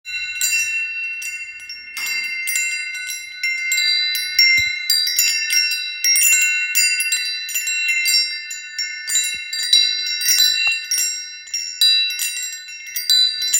Kovová zvonkohra 70cm
Kovová zvonkohra s jemnými meditačnými zvukmi, ktoré upokojujú a prinášajú pocit relaxácie.
Aj jemný vánok rozpohybuje zvončeky a vykúzli jemné tóny tejto zvonkohry.
Zvuky zvonkohry sú nenápadné a pritom krásne, takže prirodzene zapadnú do ducha záhrady.